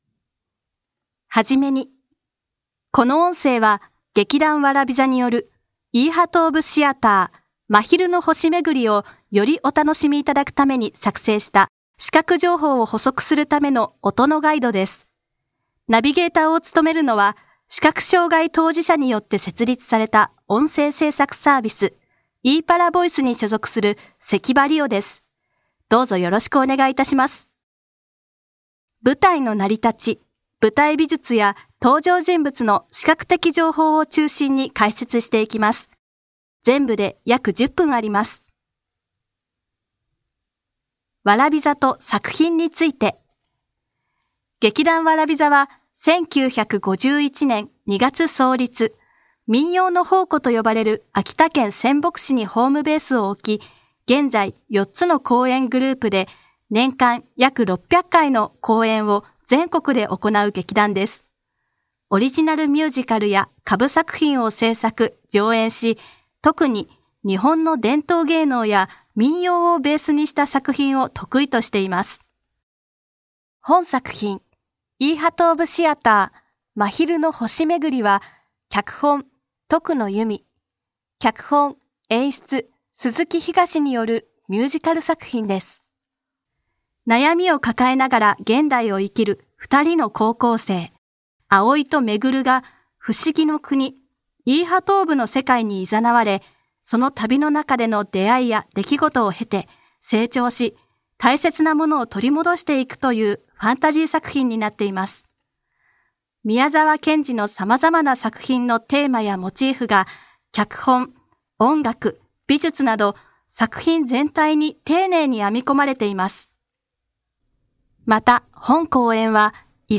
（詳細下記） 《音声解説を公開しました》 イーハトーブシアター「真昼の星めぐり」the Musicalの世界を"言葉で見る"、音声による解説。
・登場キャラクターの衣装の解説は、出演者本人の声で収録されています。